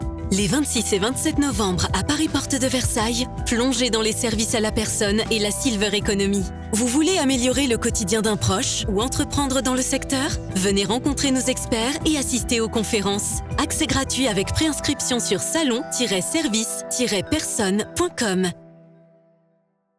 Spot_radio_Salon_SAP_Silver_Economy_2024.wav